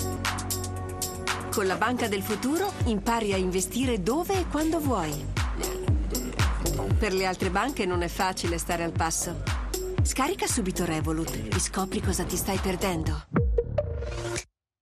Ironico - Istituzionale/Tecnico